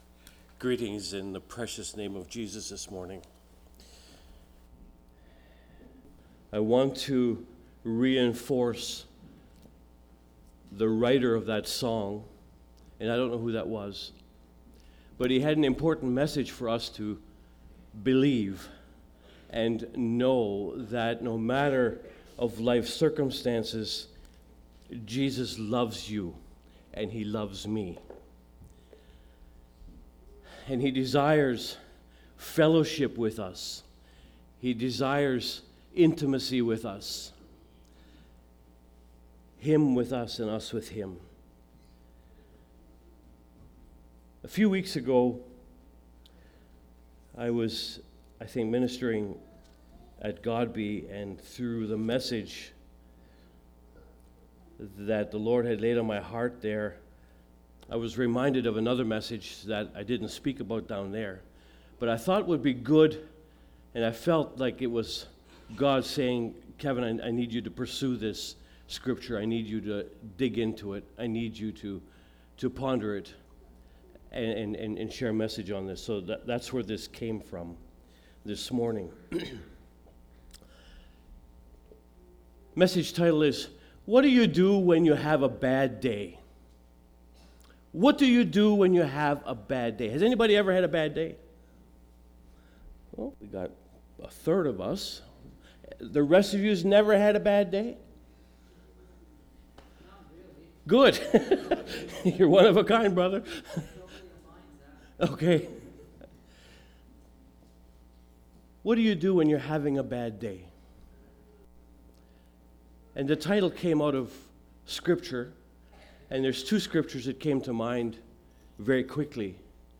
Topic: Practical Living Service Type: Sunday Sermons